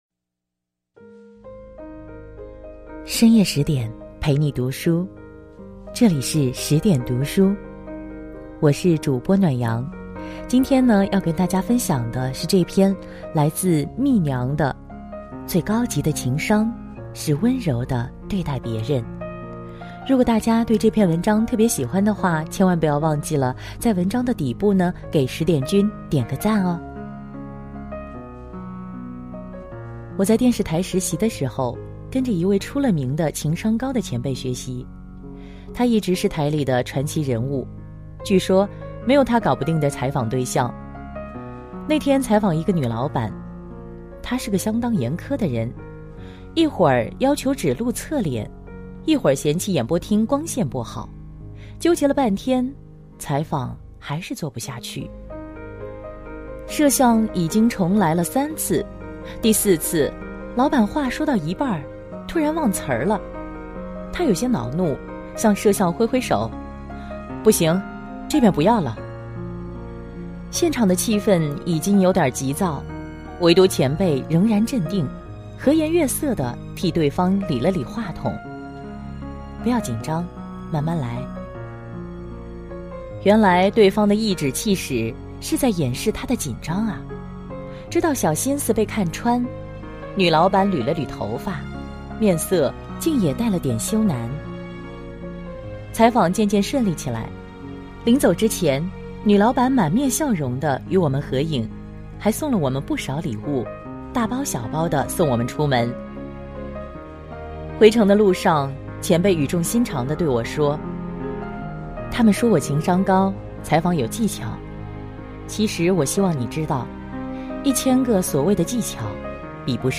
-背景音乐-
Brian Crain《A Walk In The Forest》